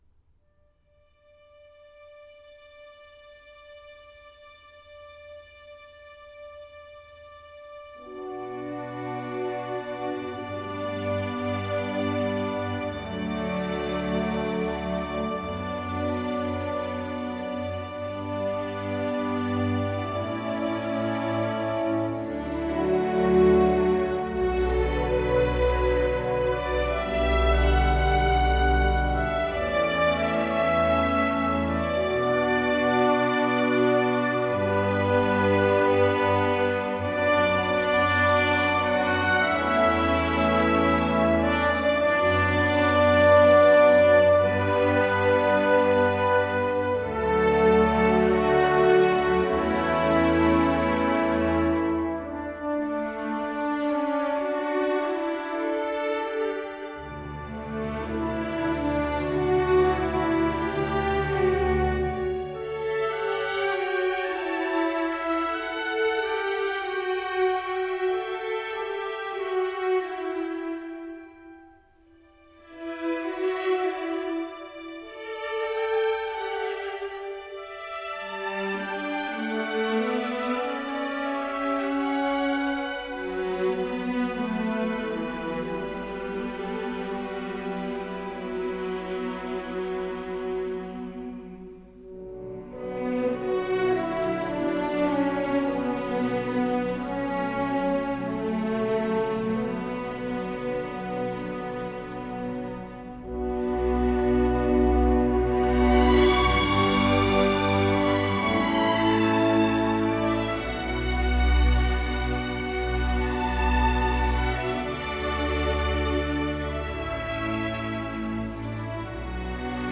Soundtrack, Classical